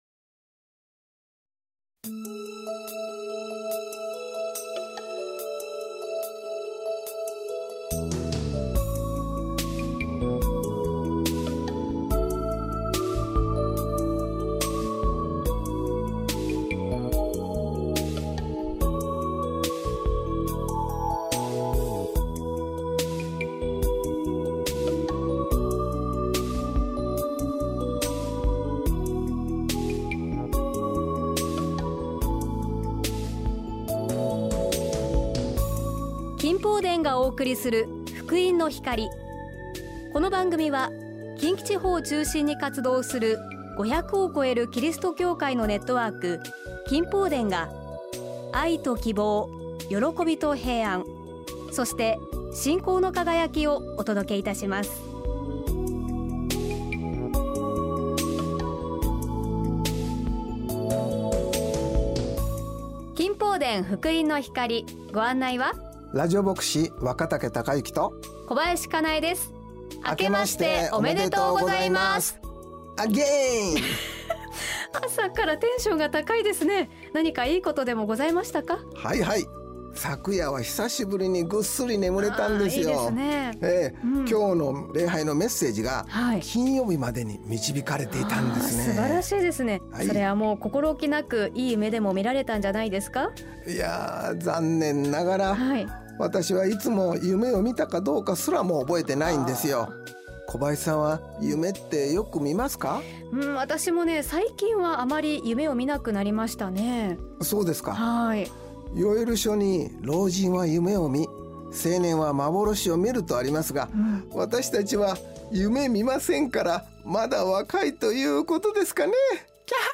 御言葉とお話「賢い実りある人生とは」